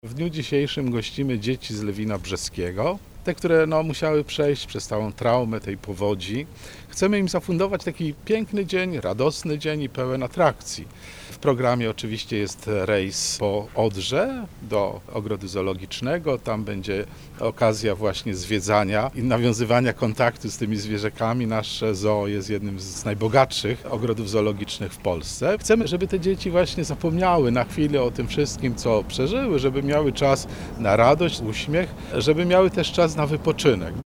Chcemy im zafundować piękny dzień, radosny dzień pełen atrakcji – podkreśla ks. arcybiskup Józef Kupny, metropolita wrocławski.